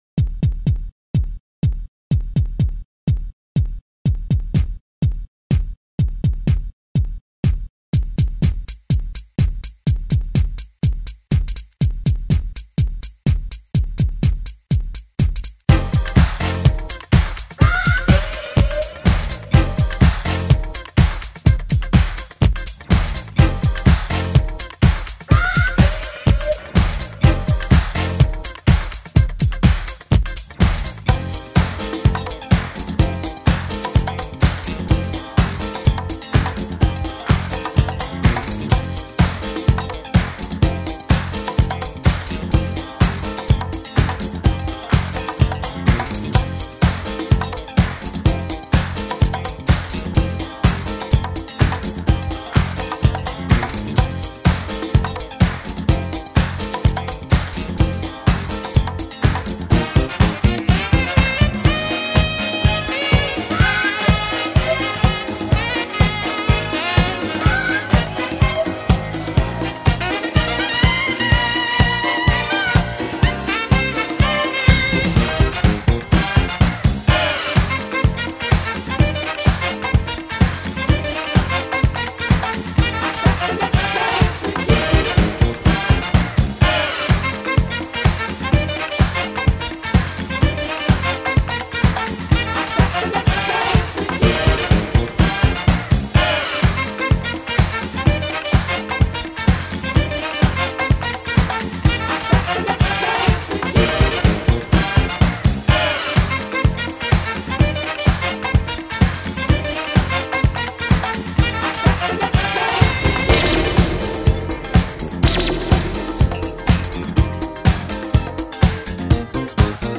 The 1979 disco hit